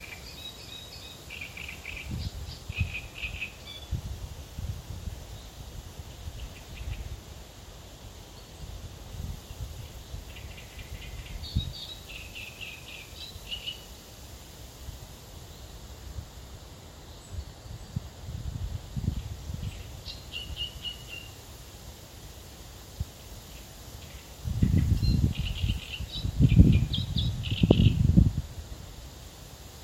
Great Reed Warbler, Acrocephalus arundinaceus
Administratīvā teritorijaRīga
StatusSinging male in breeding season